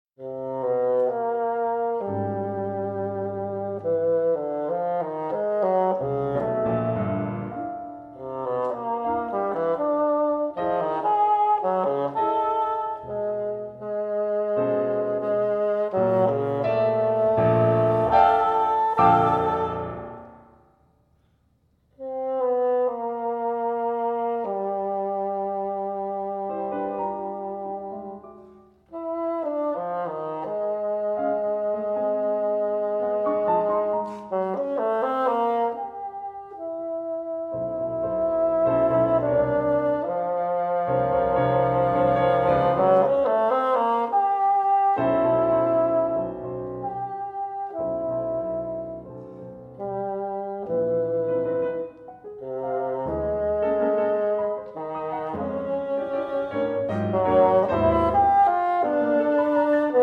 bassoon
Piano